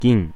Close front unrounded vowel
[ɡʲiɴ] 'silver' See Japanese phonology